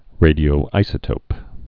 (rādē-ō-īsə-tōp)